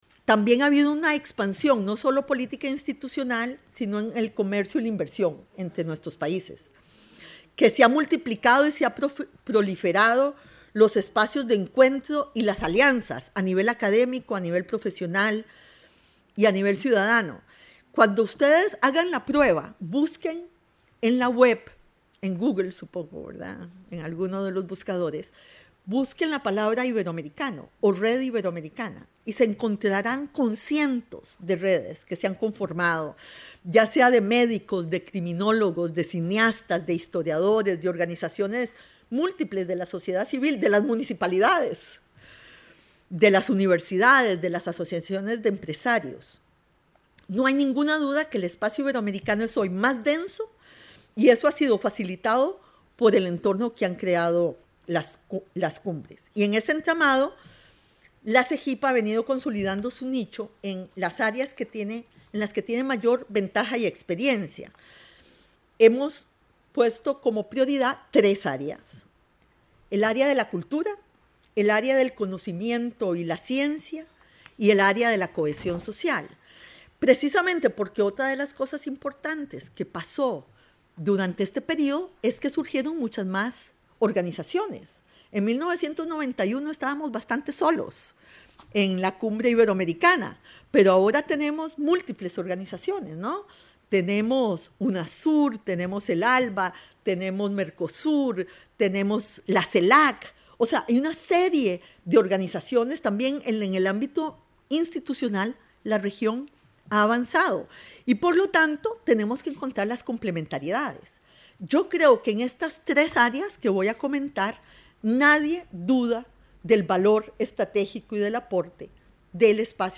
Rebeca Grynspan, Secretária-geral da SEGIB, visitou a Casa da América Latina, onde proferiu uma conferência sobre “25 anos de Cimeiras Ibero americanas – Um olhar sobre o futuro”, no dia 26 de junho.